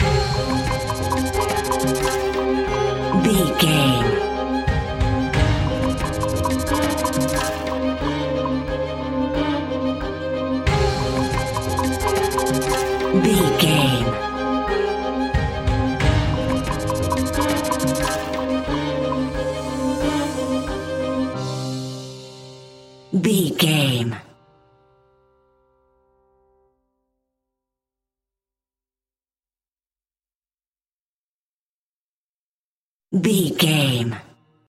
Aeolian/Minor
ominous
eerie
piano
percussion
synthesizer
horror music
Horror Pads
Horror Synths